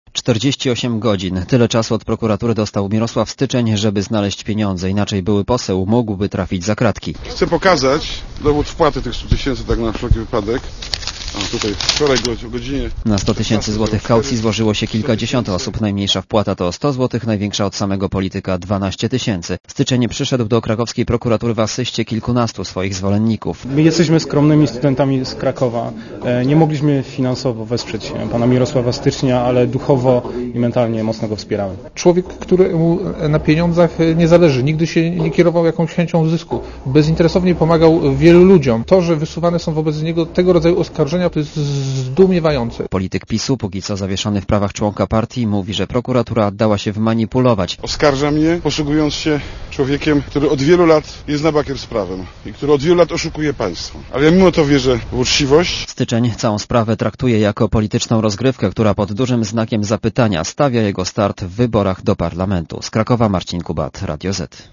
Relacja reportera Radia Zet Polityk przekazał dowód wpłaty prokuraturze, wcześniej pokazał go dziennikarzom.